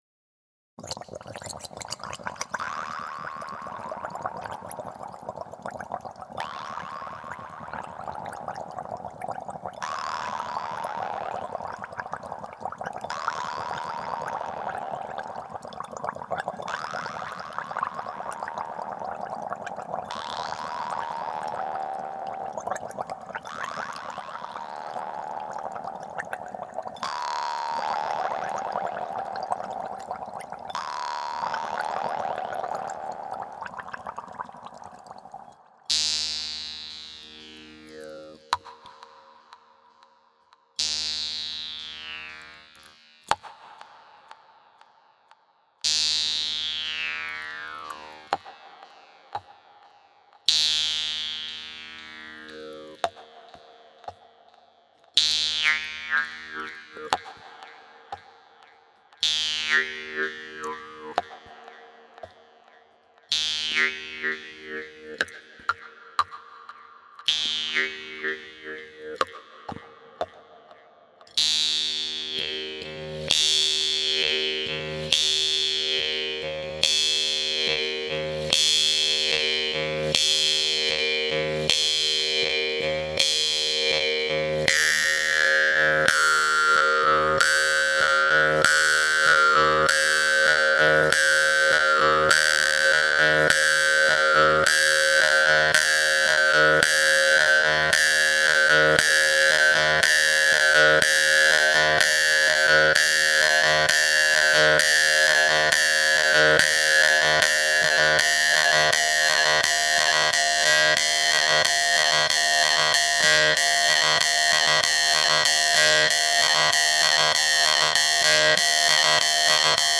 Live Jew's-Harp
Genre: Electronic.